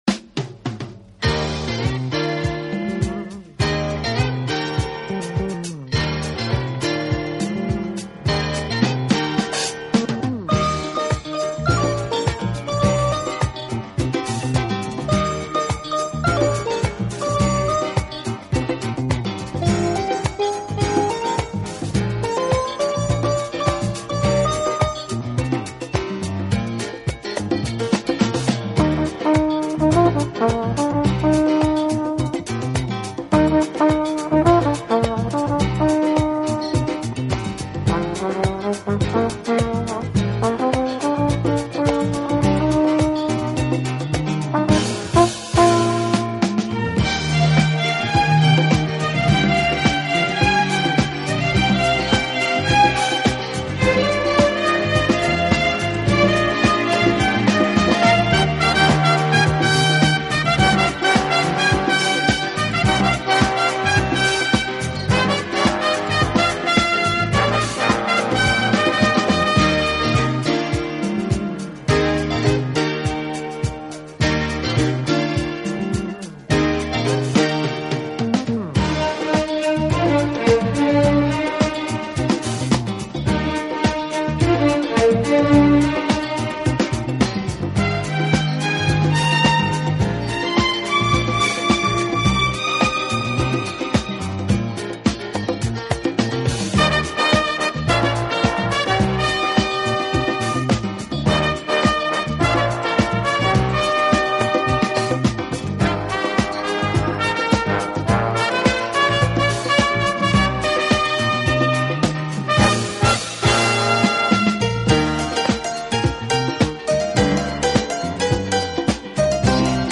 提琴”弦乐队。